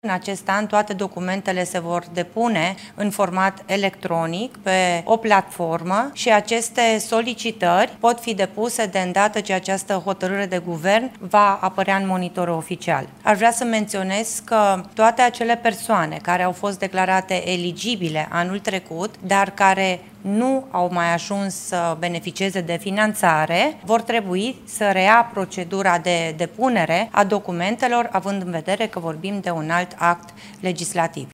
Ministrul Familiei, Natalia Intotero: „Persoanele care au fost declarate eligibile anul trecut, dar care nu au mai ajuns să beneficieze de finanțare vor trebui să reia procedura”